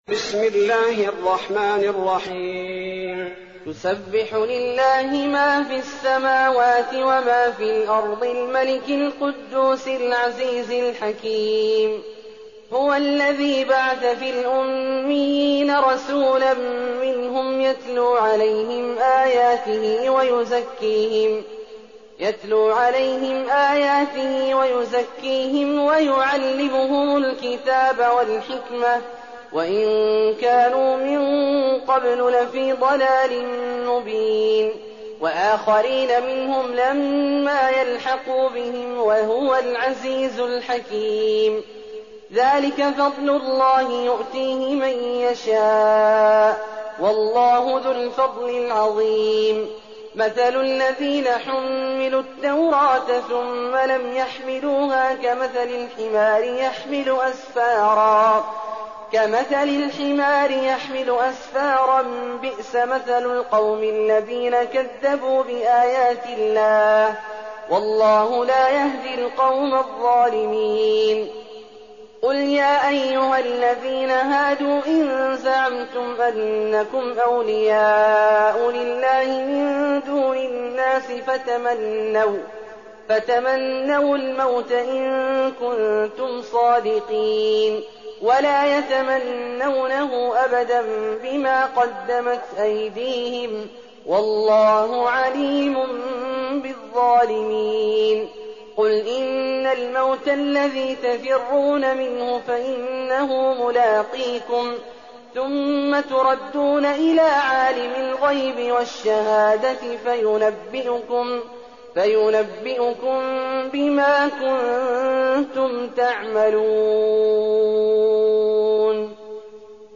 تراويح ليلة 27 رمضان 1419هـ من سورة الجمعة الى التحريم Taraweeh 27th night Ramadan 1419H from Surah Al-Jumu'a to At-Tahrim > تراويح الحرم النبوي عام 1419 🕌 > التراويح - تلاوات الحرمين